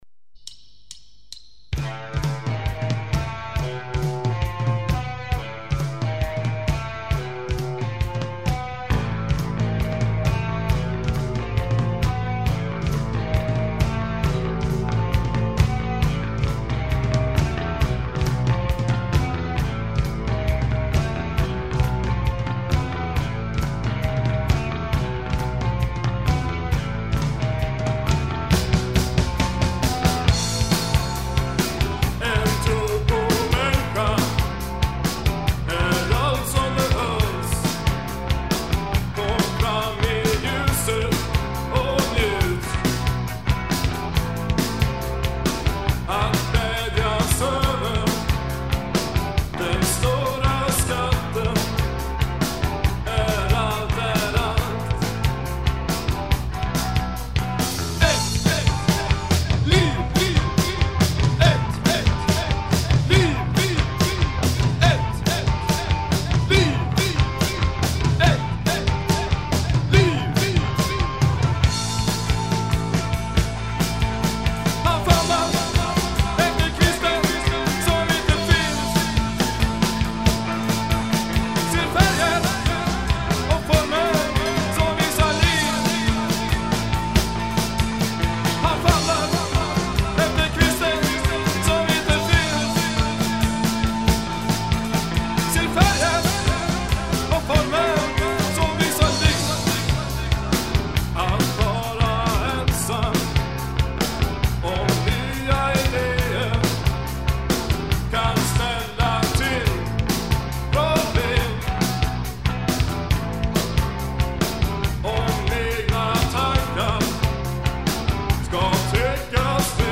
Bass
Guitar
Voice
Drums